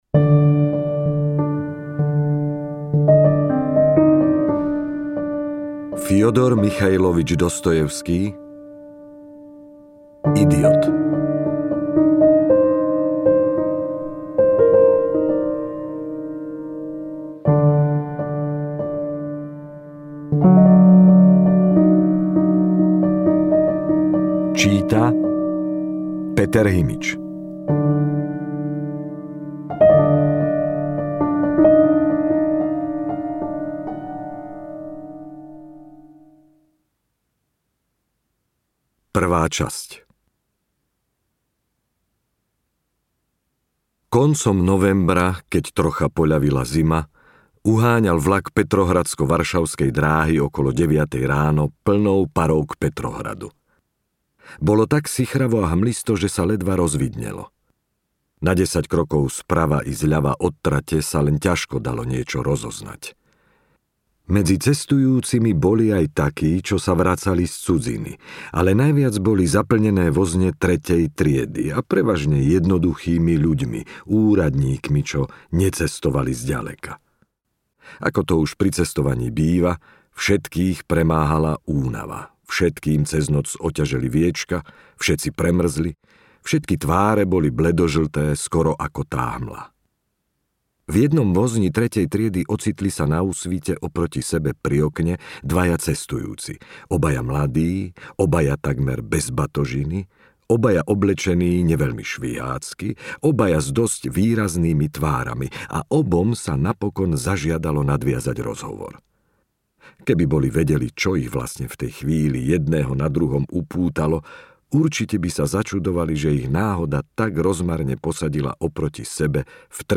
Idiot audiokniha
Ukázka z knihy